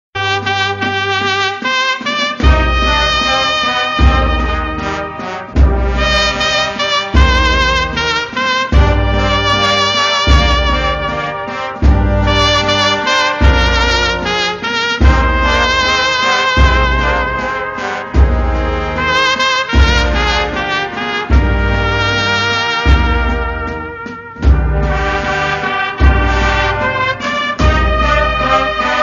Categoria Clássico